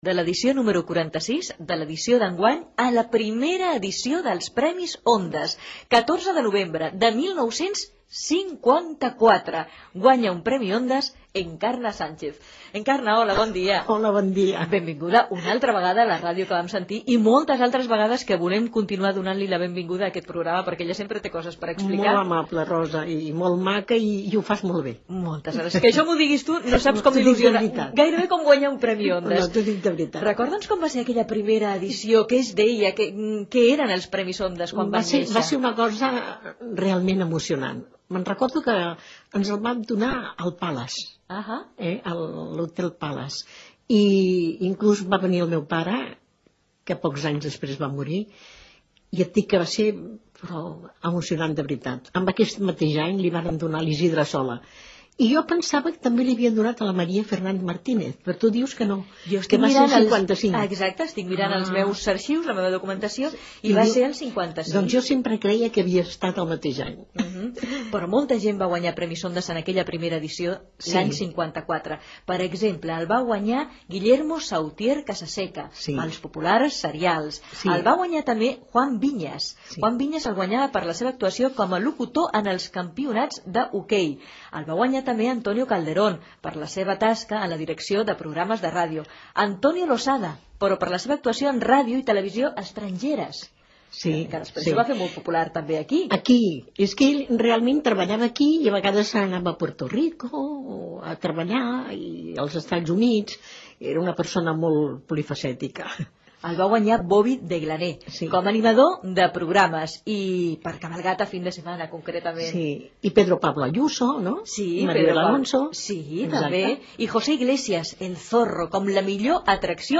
Divulgació
Programa emès amb motiu del 75 aniversari de Ràdio Barcelona.